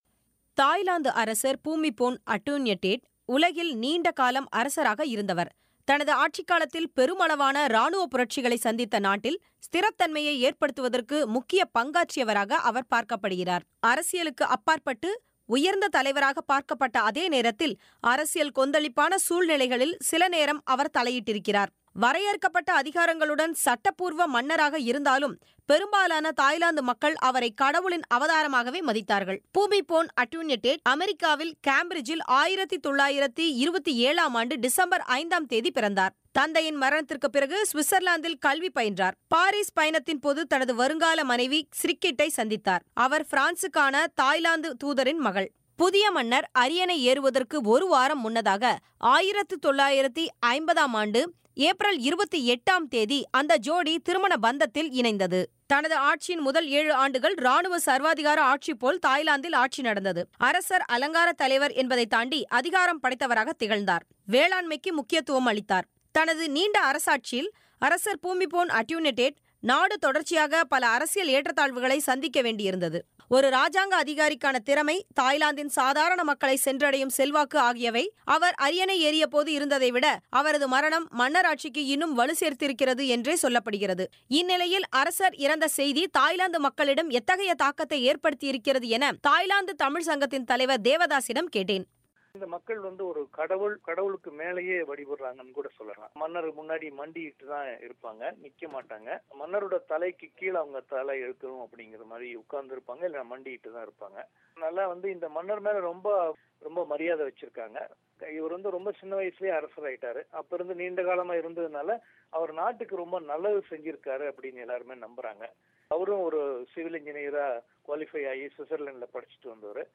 காலமான தாய்லாந்து அரசர் பூமிபோன் அடூன்யடேட் வாழ்க்கைக் குறிப்பு மற்றும் தாய்லாந்தில் உள்ள நிலை குறித்த தமிழரின் பேட்டி